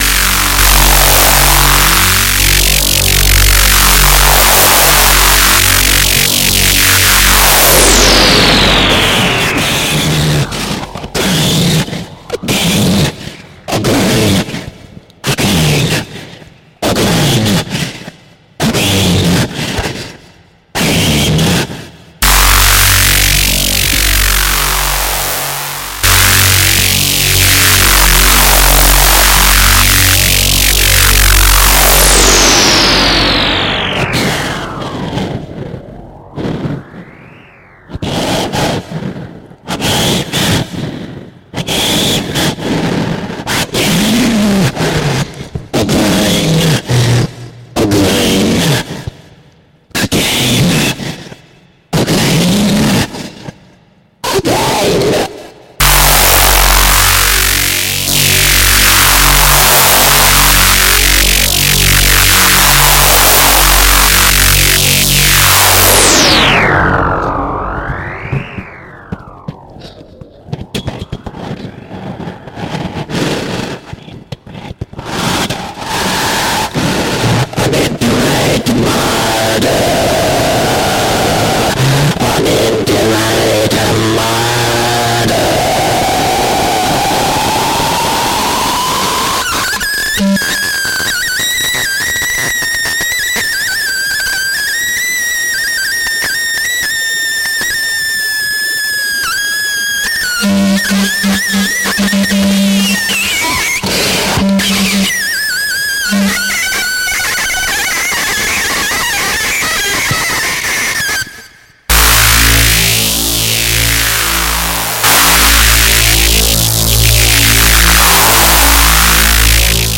high-pitched drones
• Genre: Death Industrial / Power Electronics